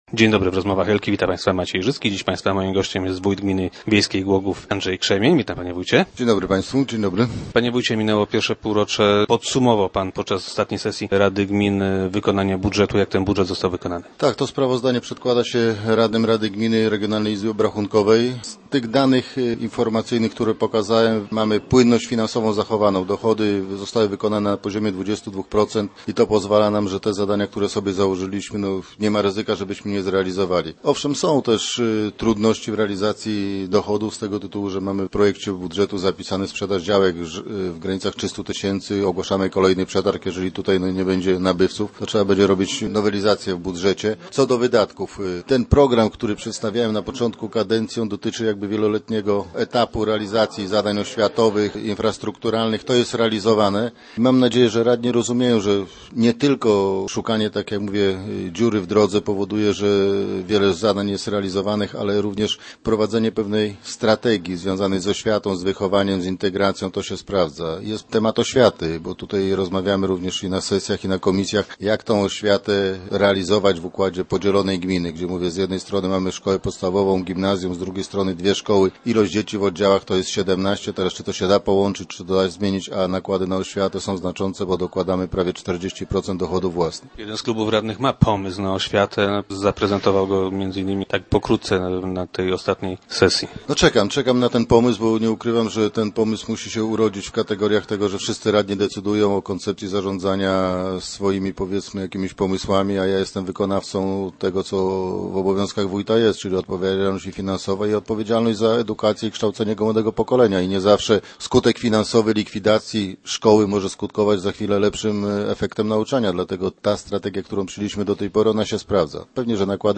Niestety potencjalni inwestorzy coraz rzadziej pytają o takie tereny. Gościem Rozmów Elki był Andrzej Krzemień, wójt gminy wiejskiej Głogów.